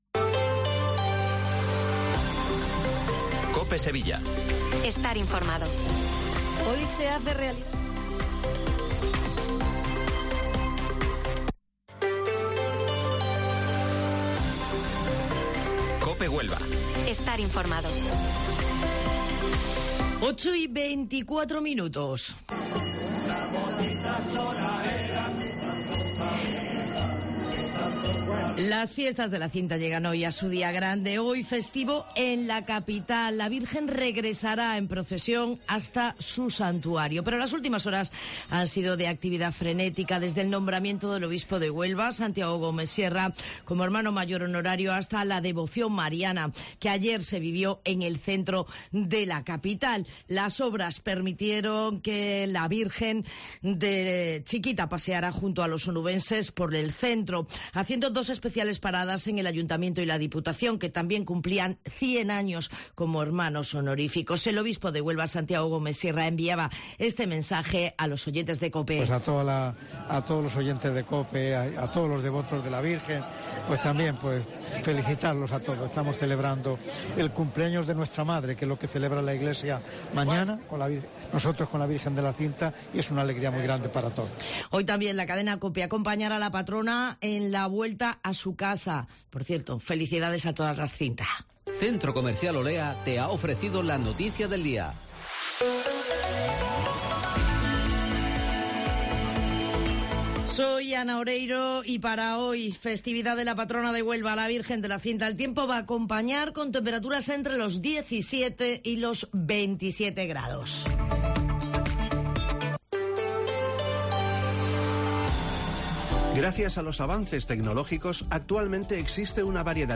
Informativo Matinal Herrera en COPE 8 de septiembre